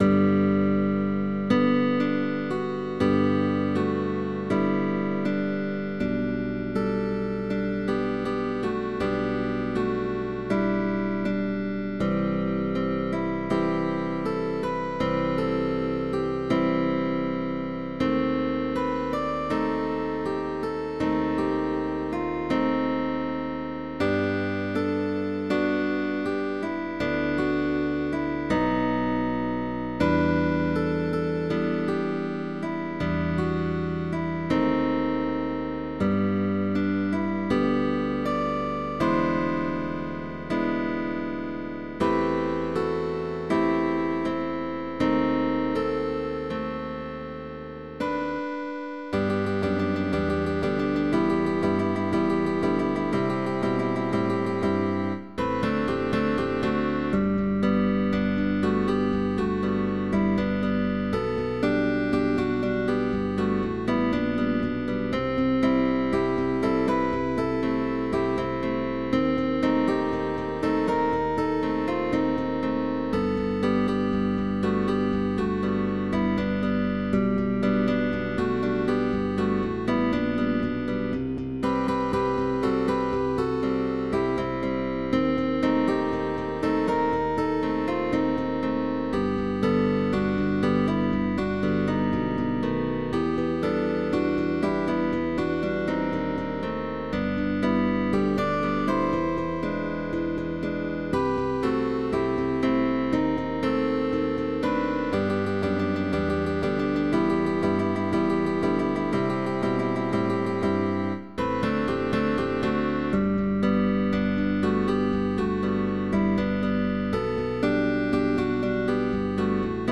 TRÍO de GUITARRAS
Con bajo opcional, válido para orquesta de guitarras.
Autor: Pop music